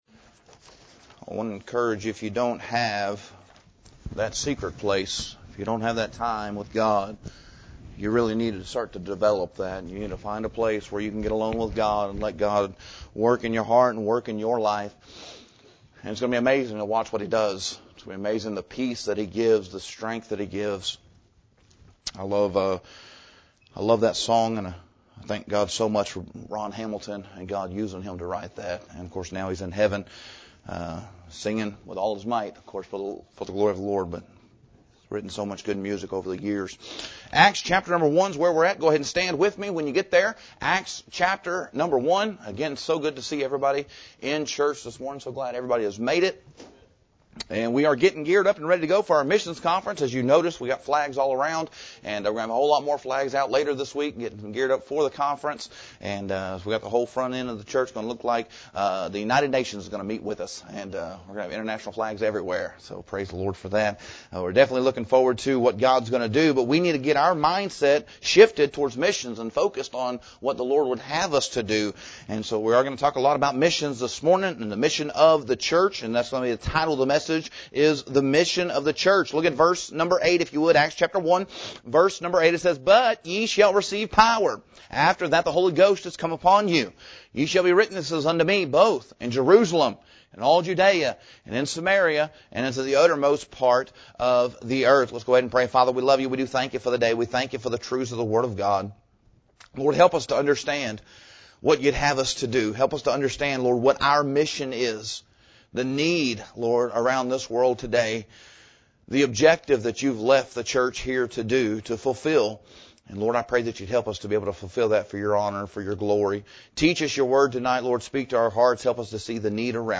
The Mission of the Church – Cornerstone Baptist Church | McAlester, OK